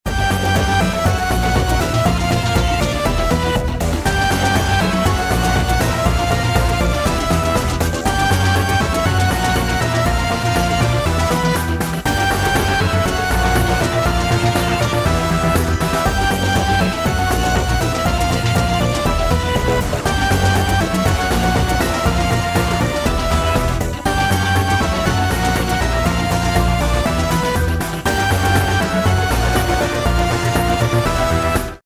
midi-demo 3